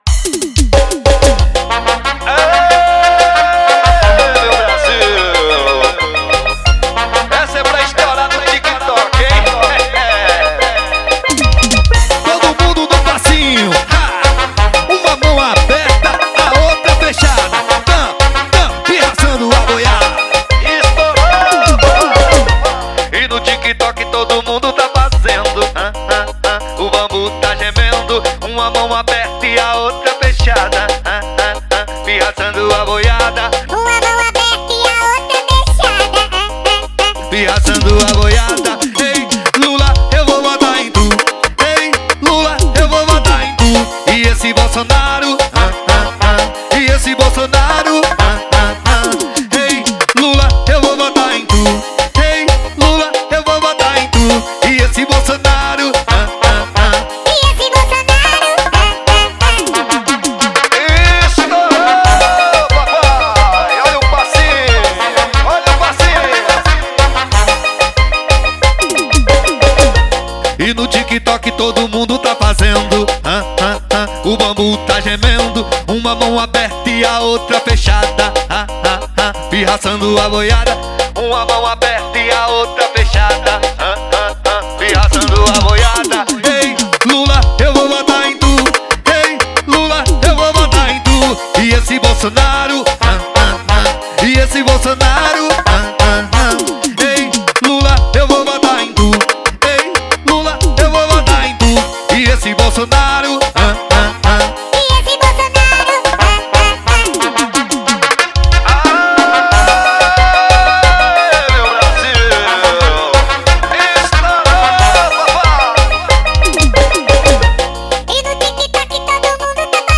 2025-01-06 00:31:12 Gênero: Axé Views